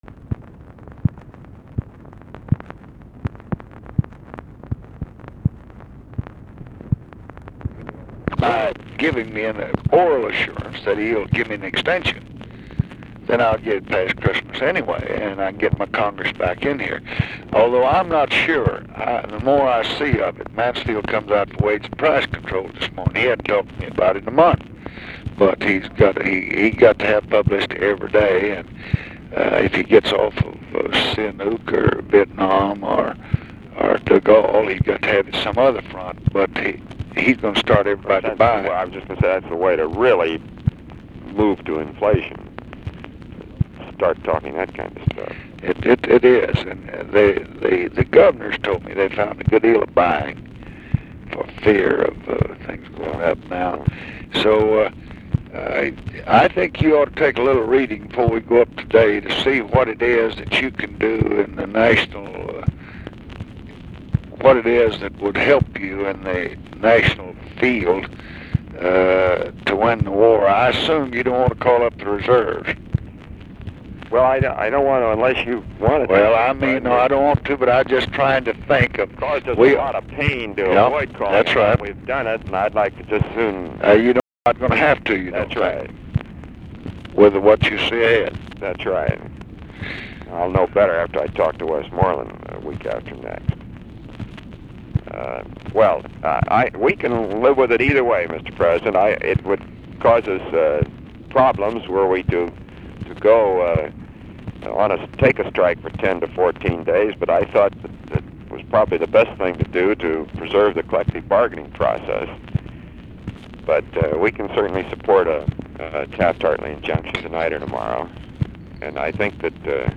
Conversation with ROBERT MCNAMARA, October 1, 1966
Secret White House Tapes